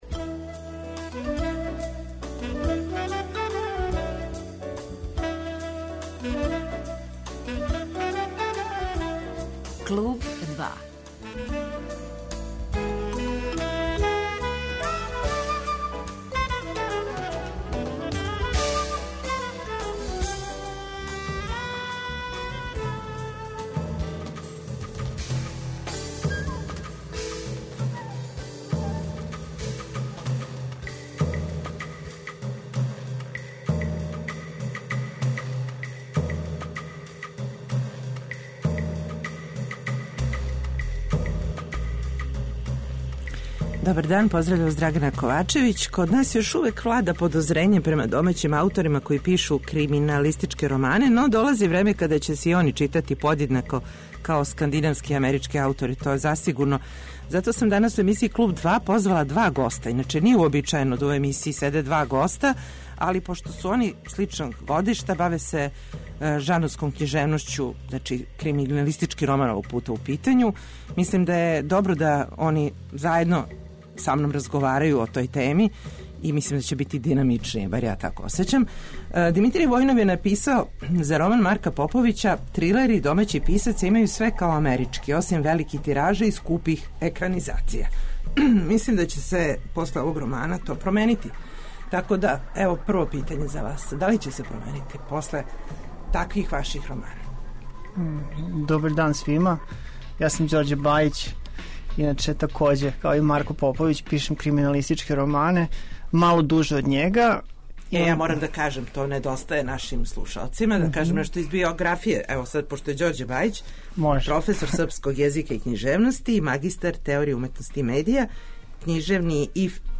Зато смо данас у студио Радио Београда 2 позвали два аутора који су написали романе о којима се говори и који могу стати уз раме најбољим страним писцима овог жанра.